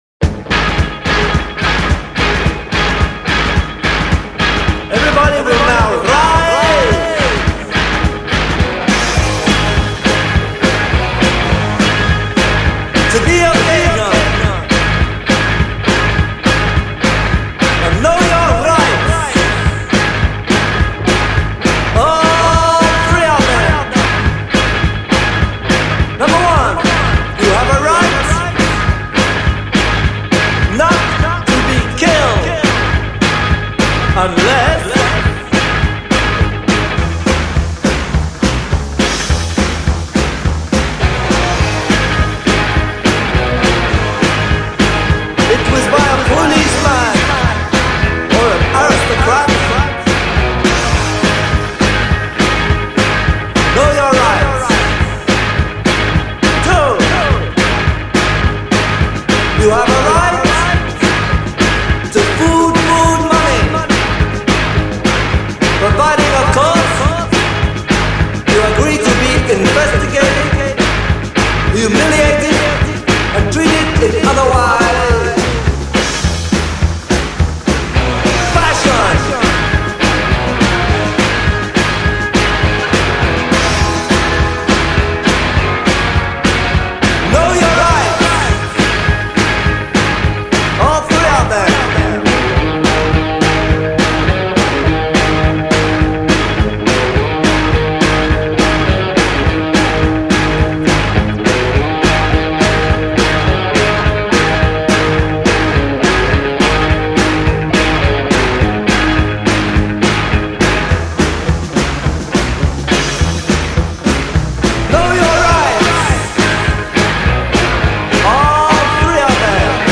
Punk Rock, Reggae, New Wave